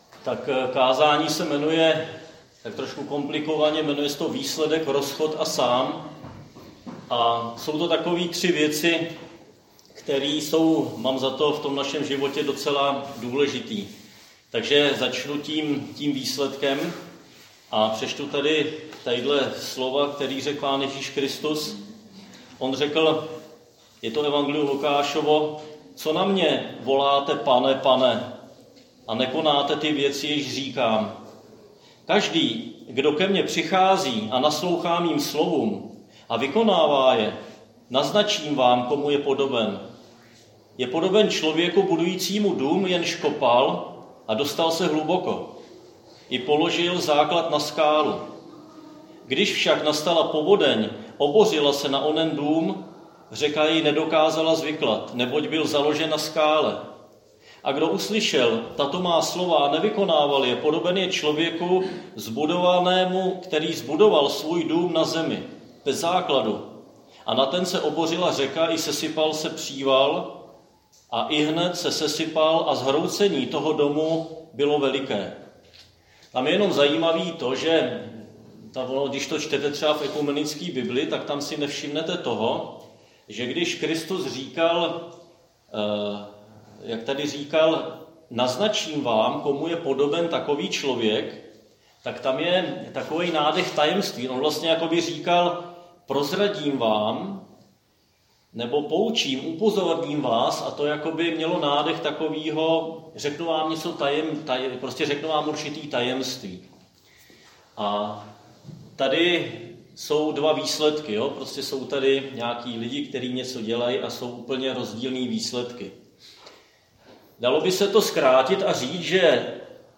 Křesťanské společenství Jičín - Kázání 22.8.2021